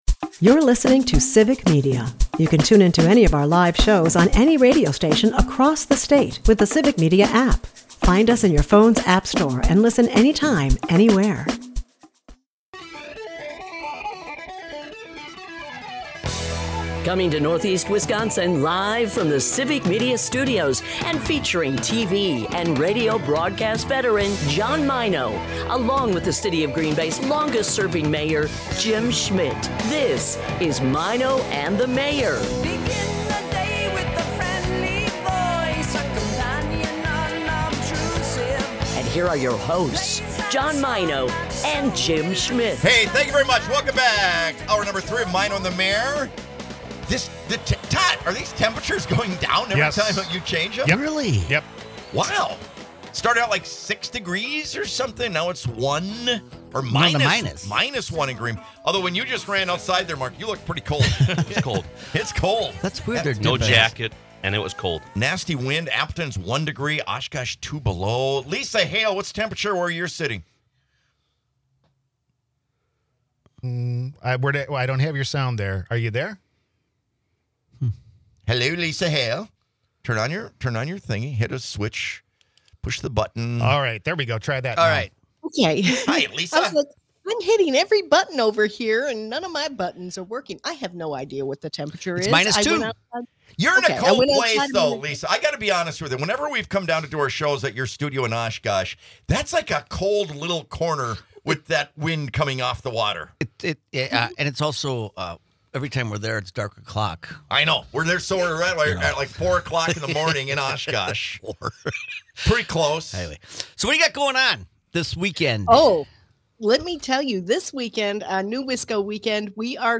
Another great hour from Heights Pub & Parlor!